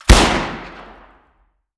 weap_juliet_launch_atmo_int_02.ogg